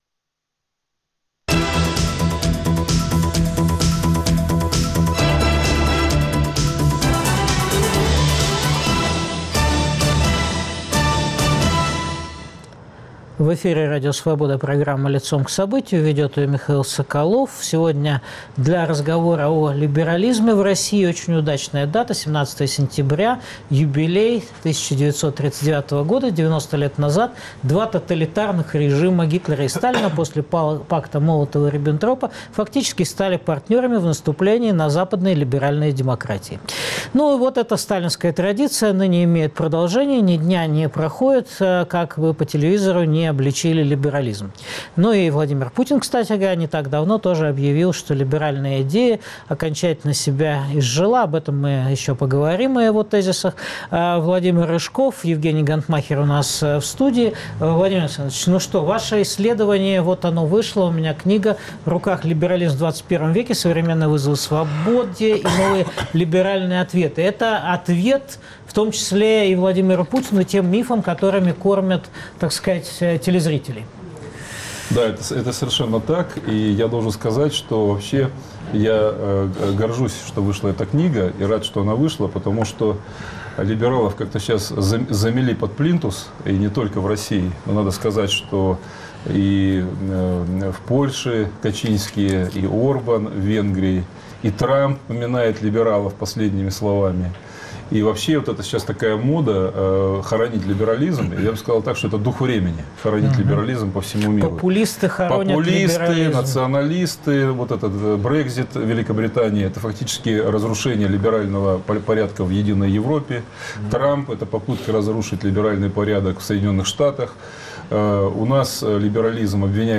Что мешает создать России свободную социальную либеральную демократию как в Западной Европе? Обсуждают политик и историк Владимир Рыжков и экономист Евгений Гонтмахер.